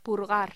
Locución: Purgar
voz
locución
Sonidos: Voz humana